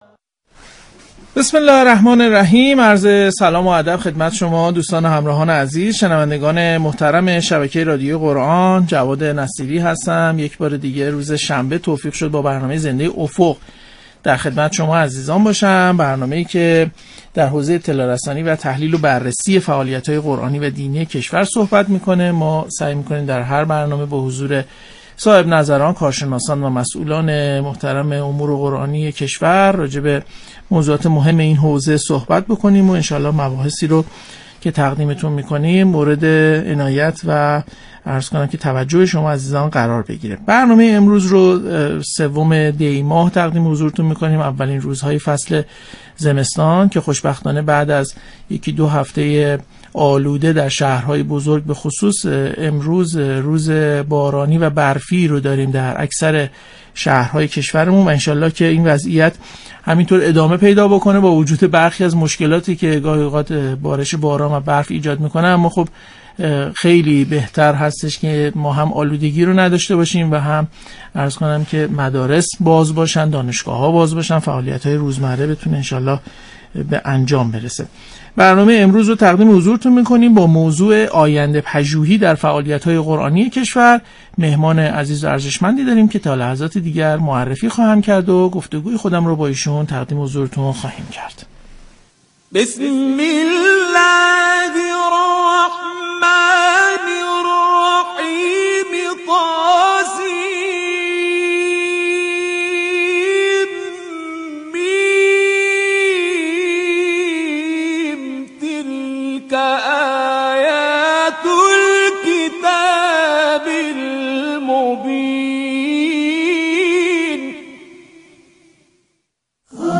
عضو کمیسیون توسعه فعالیت‌های تبلیغی و ترویجی قرآن در برنامه افق رادیو قرآن ضمن بیان ضرورت‌های آینده‌پژوهی در حوزه قرآن کریم، گفت: در فعالیت‌های قرآنی کشور نگاه علمی به آینده‌پژوهی وجود ندارد و مدیران نیز نگاه جدی به این حوزه ندارند.